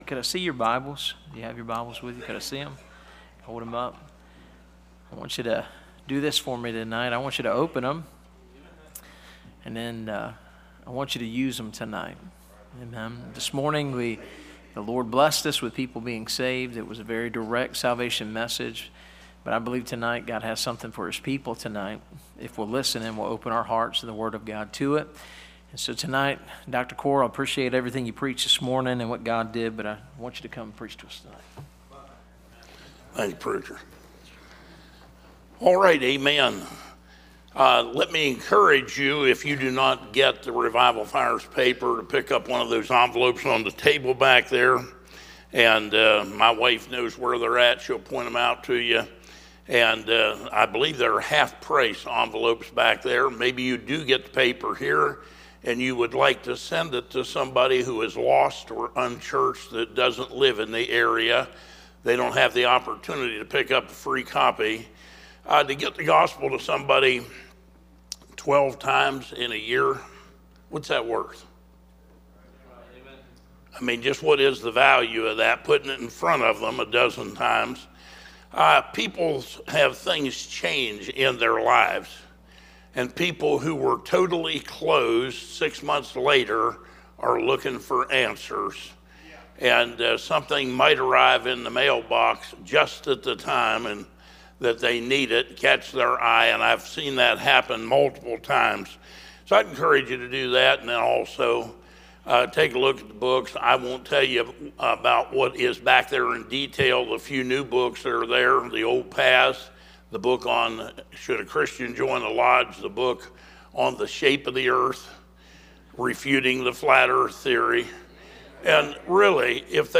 Series: 2025 Bible Conference